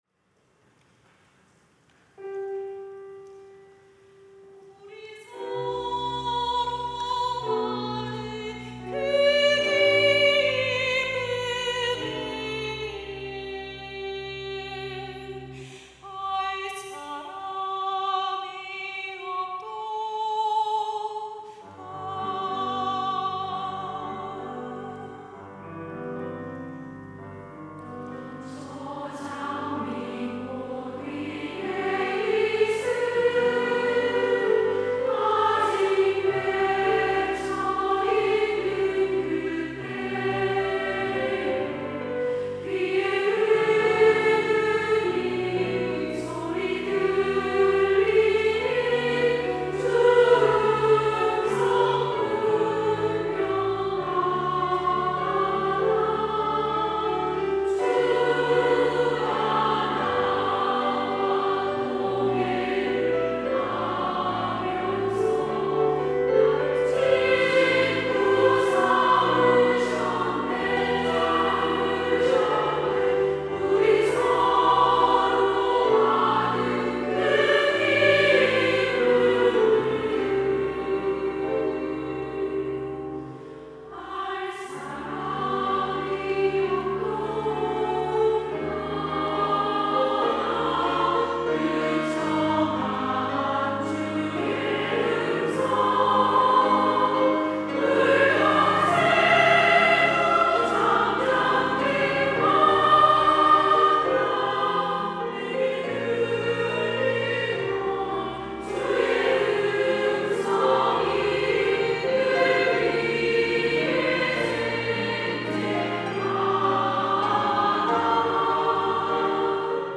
여전도회 - 저 장미꽃 위에 이슬
찬양대